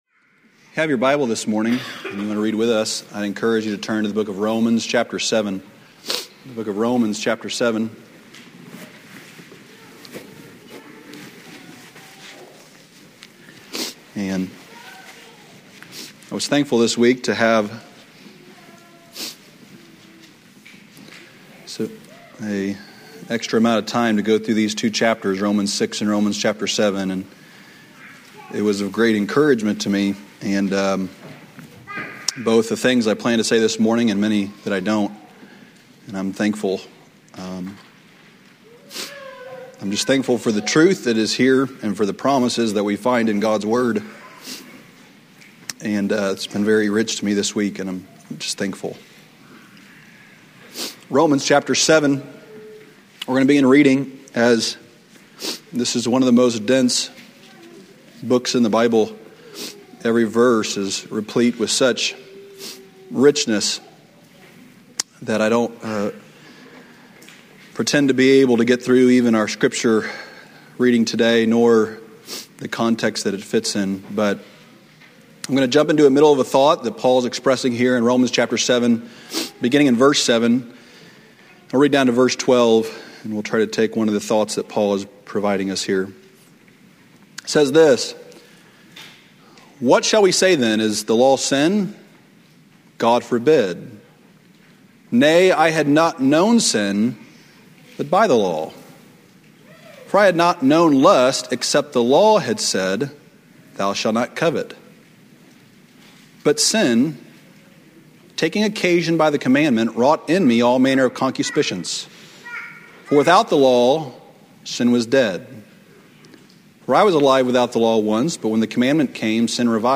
A message from the series "Revival Sermons."